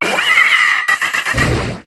Cri de Malamandre dans Pokémon HOME.